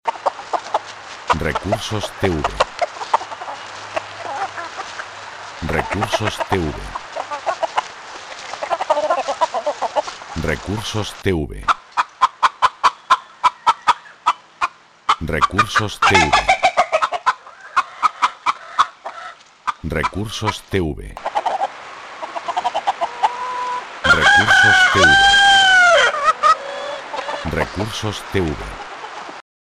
Sonido de granja, gallinas y gallo
gallinero_0.mp3